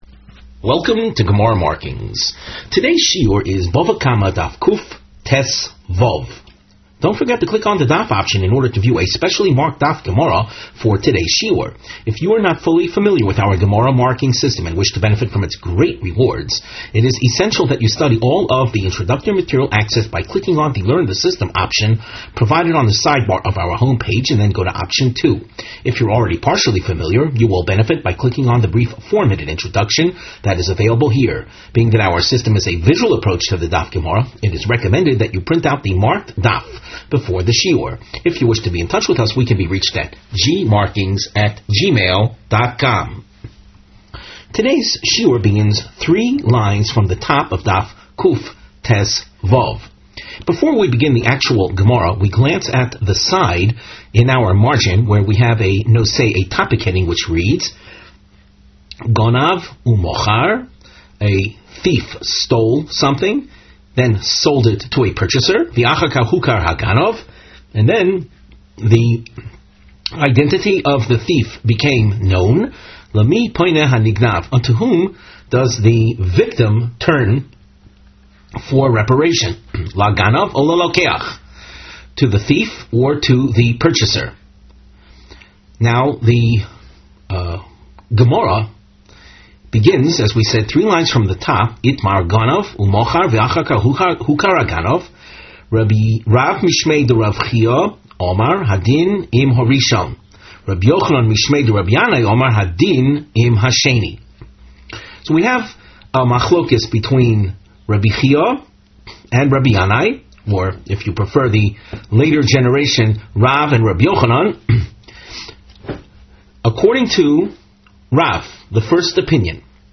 Please click on the above video to hear the Rav give the shiur.